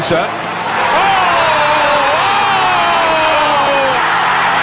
Goal Wooooow Sound Effect Free Download
Goal Wooooow